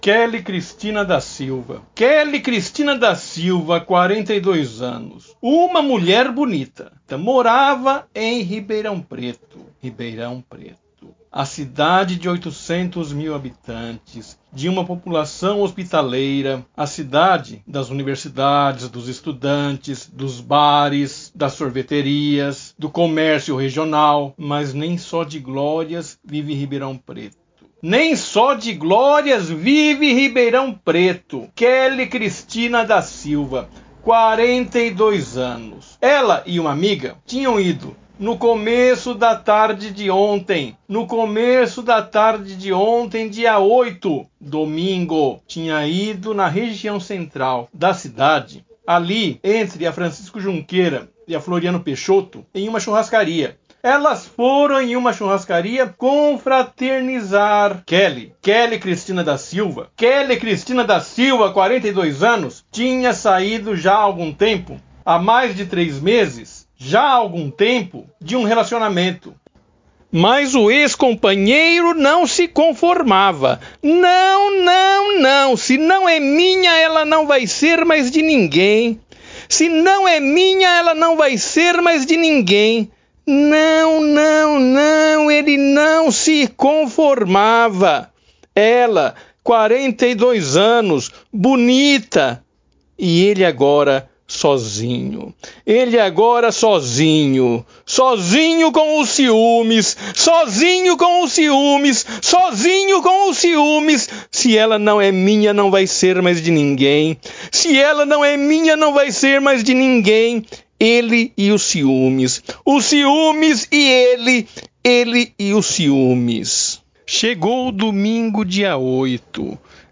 Ouça a íntegra da matéria com a narração